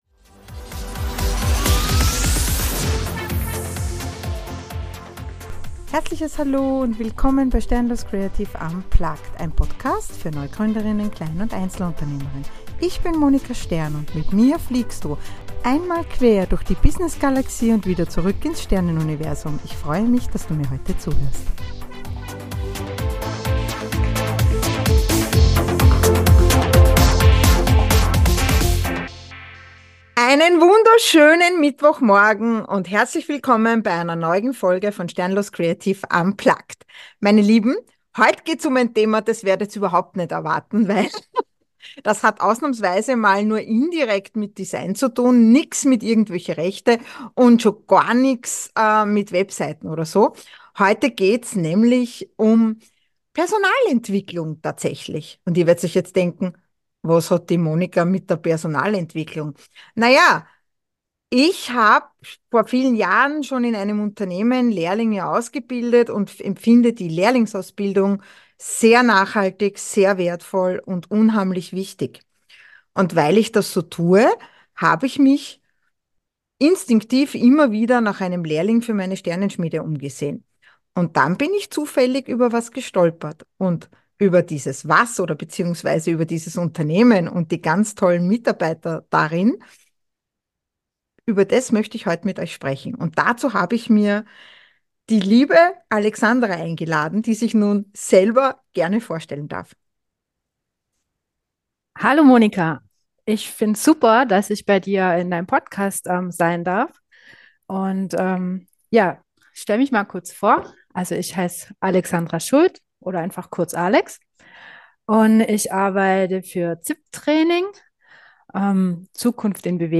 INTERVIEW Manchmal ist das Leben nicht gerecht. Und manchmal brauchen wir alle einfach eine Chance.